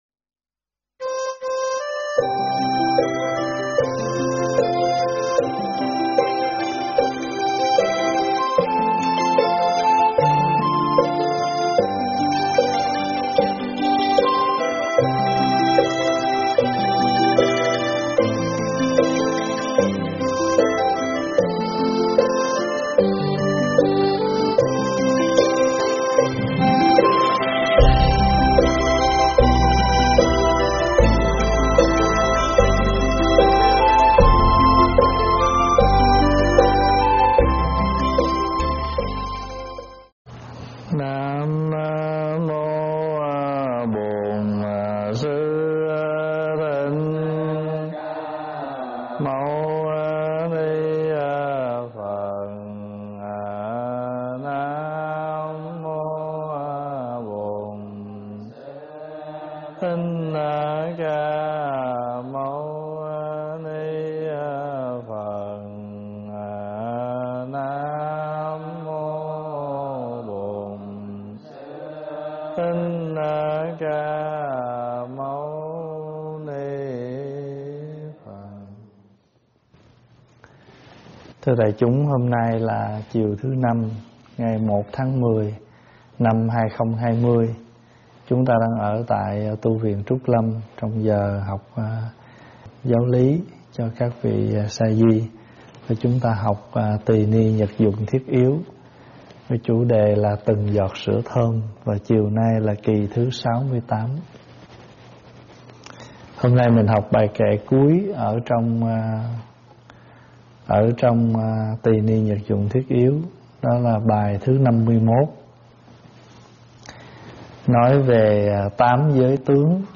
Nghe mp3 thuyết pháp Từng Giọt Sữa Thơm 68 - Bát quan trai giới tướng
giảng tại Tv Trúc Lâm